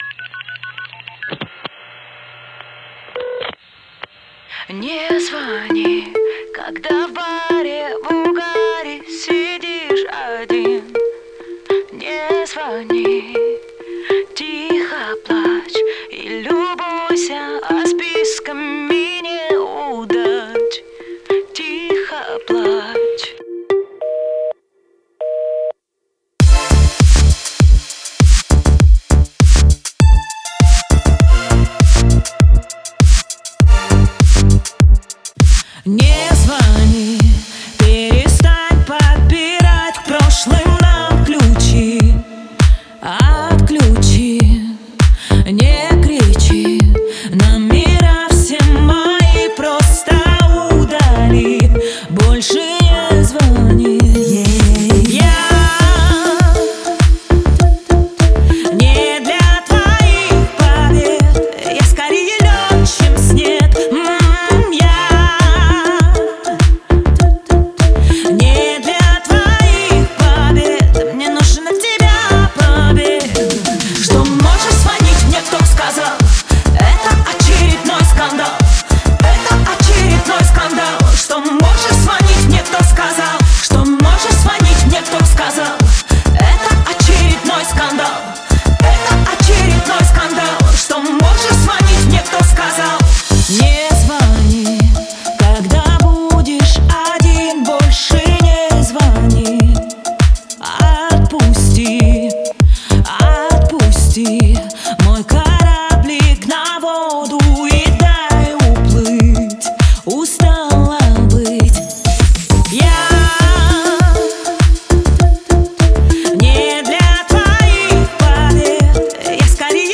это мощный поп-трек с элементами R&B